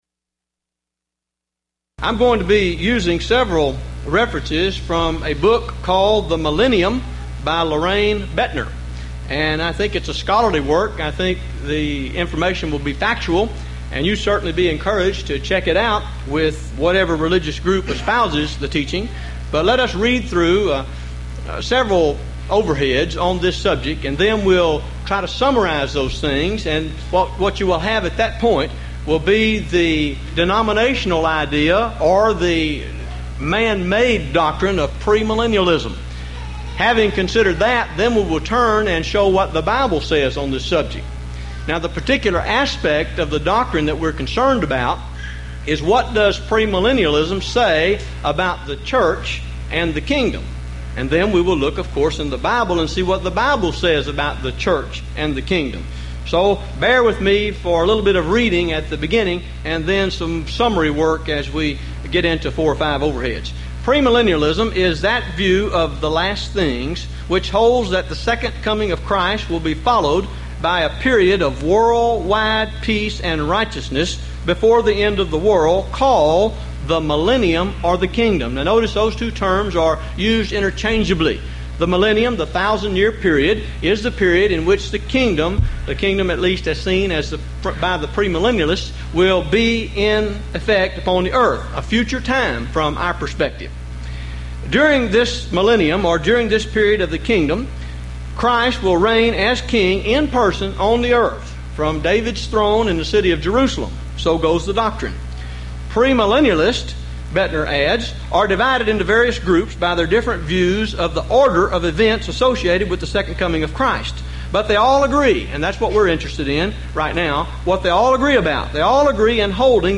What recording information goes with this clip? Series: Houston College of the Bible Lectures Event: 1997 HCB Lectures